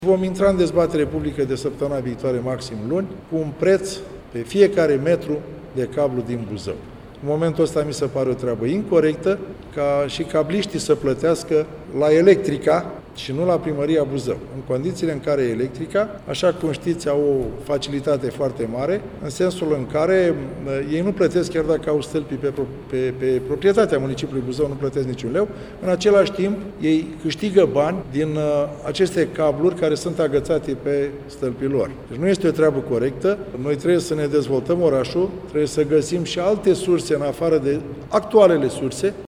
De altfel, acest fapt a fost menționat recent de către primarul Constantin Toma care nu consideră corectă varianta ca aceste sume să meargă în bugetul societății Electrica: